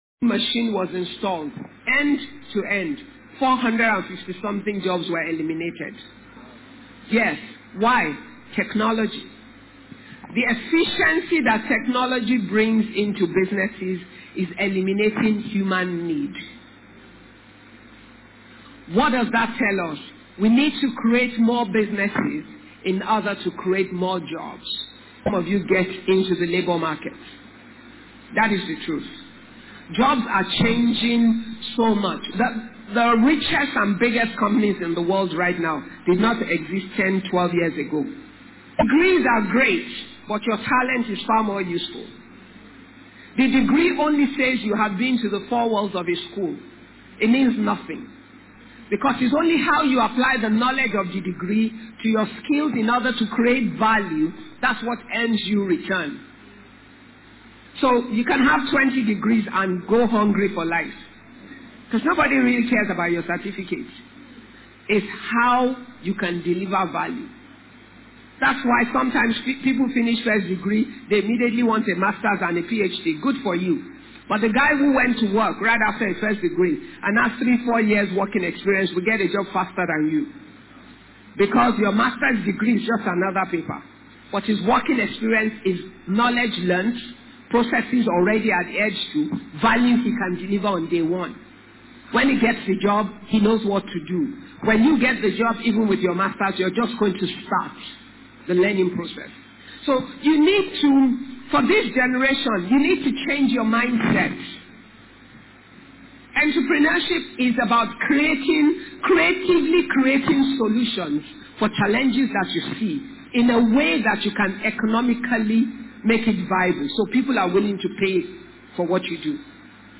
Listen to Dr. Ibukun Awosika as she shares profound insights on creating a business that Prospers. Use the download button below to download this seminar.